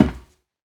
StepMetal3.ogg